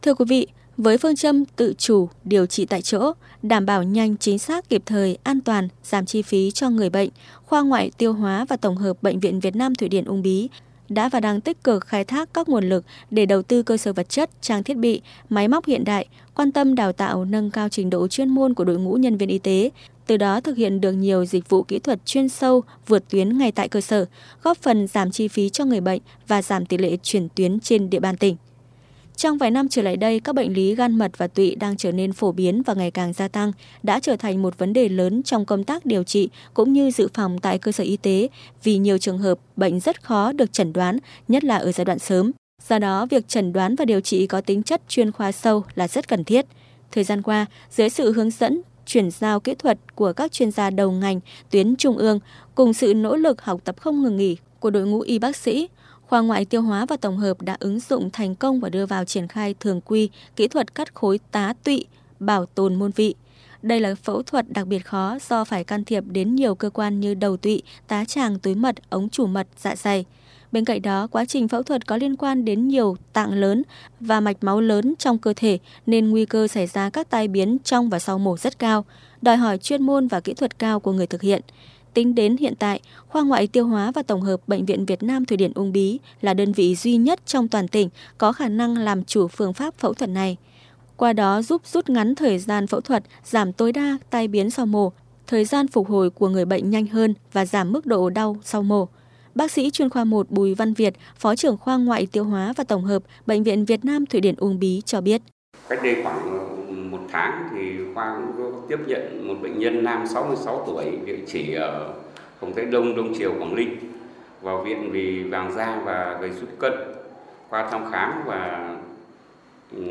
Từ đó, thực hiện được nhiều dịch vụ kỹ thuật chuyên sâu vượt tuyến ngay tại cơ sở, góp phần giảm chi phí cho người bệnh và giảm tỉ lệ chuyển tuyến trên địa bàn tỉnh. Mời quý vị và các bạn cùng lắng nghe qua phóng sự phát thanh: